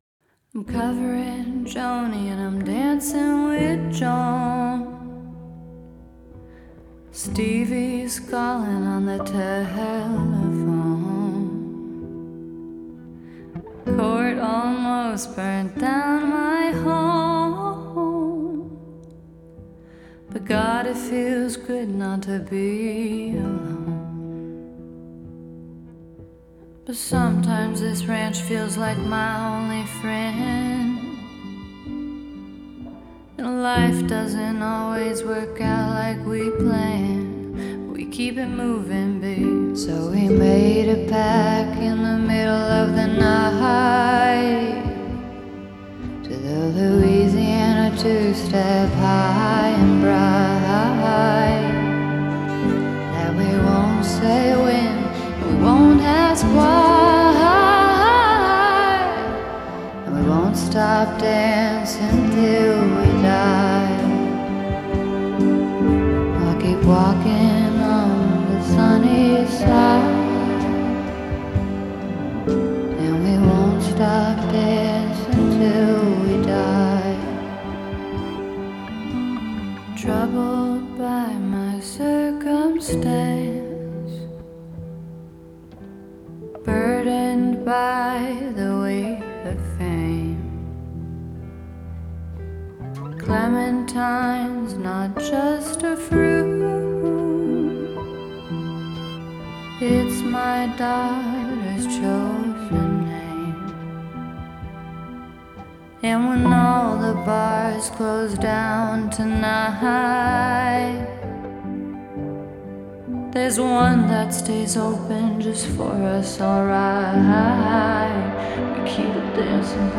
яркая и меланхоличная композиция
выполненная в жанре поп с элементами инди и электроники.